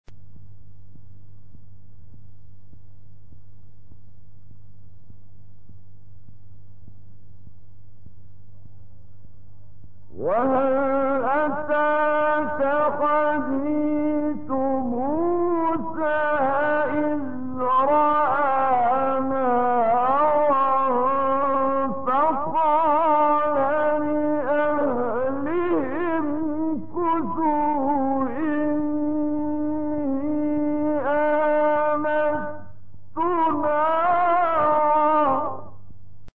گروه شبکه اجتماعی: فرازهای صوتی از کامل یوسف البهتیمی که در مقام بیات اجرا شده است، می‌شنوید.